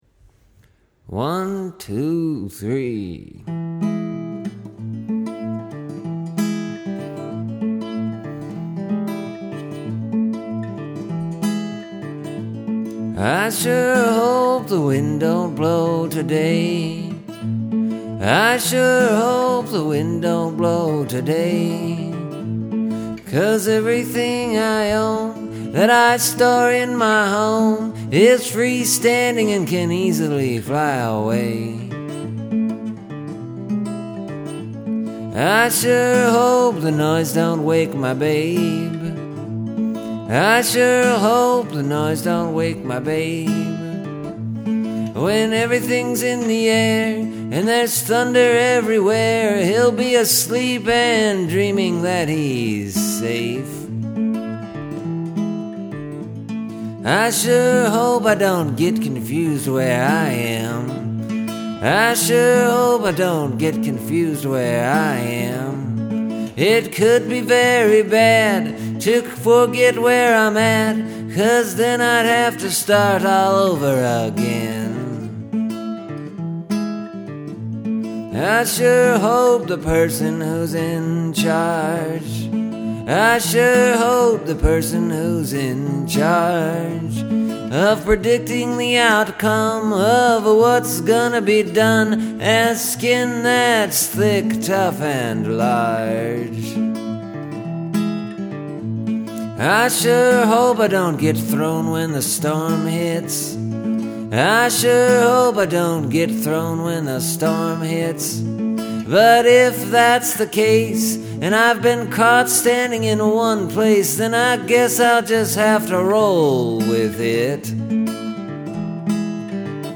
And early this morning I woke up and finished it off and recorded it. You can kinda hear the pre-dawn in my voice.
Start getting colder.
This song, today, is played in standard, 6-string guitar tuning. It’s finger-picked. It uses bar-chords.
No capos were used.